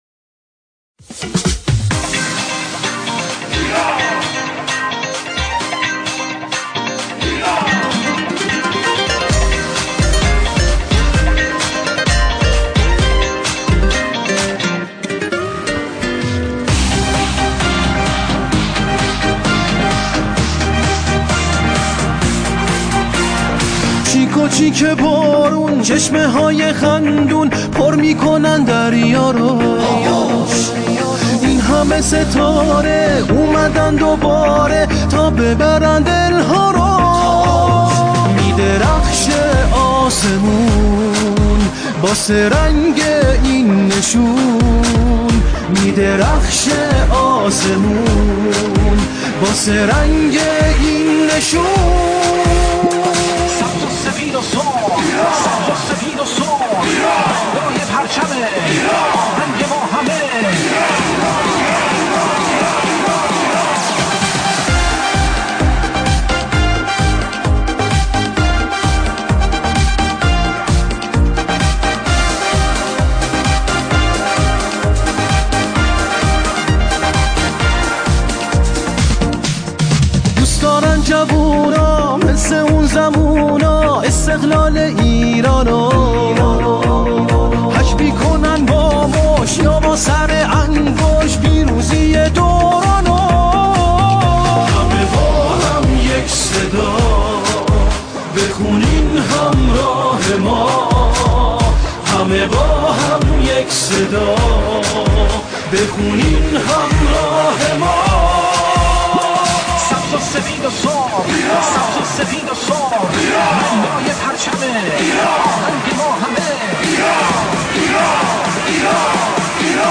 سرودهای ورزشی
این آهنگ نمونه‌ای از جمعخوانی با دو خواننده اصلی است.